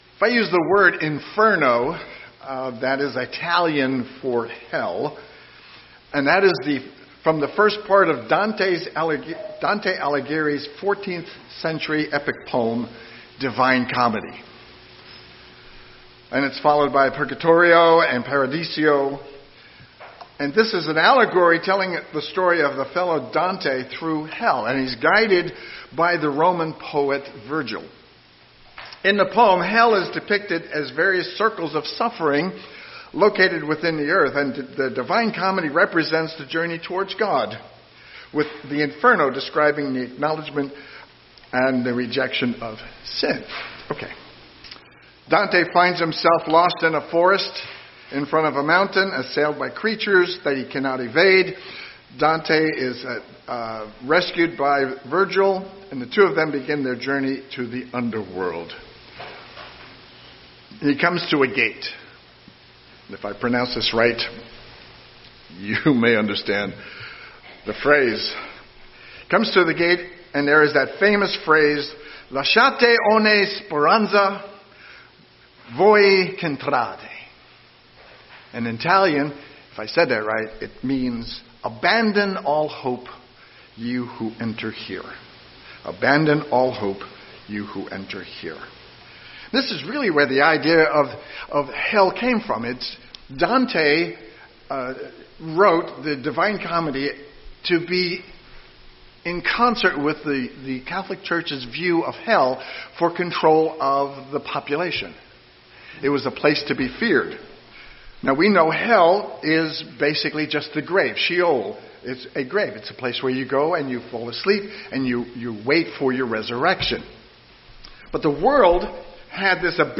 Sermons
Given in Eureka, CA